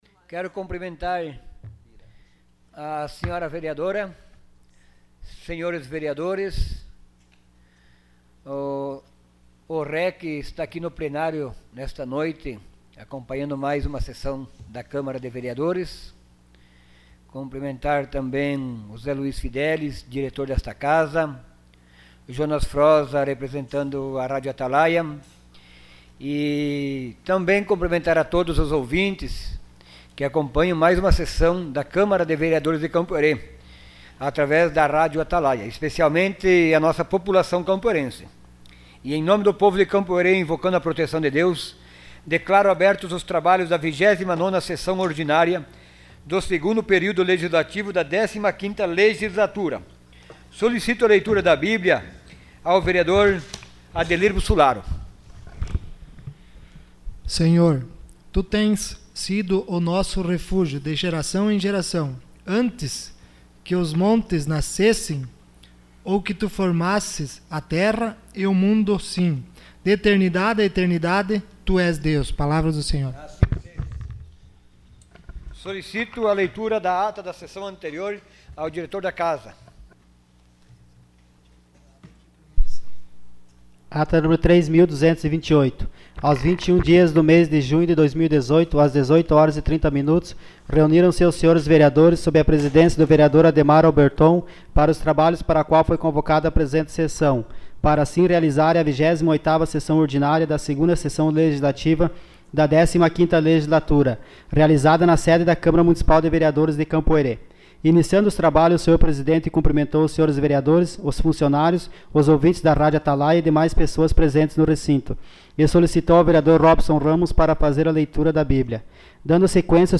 Sessão Ordinária dia 25 de junho de 2018.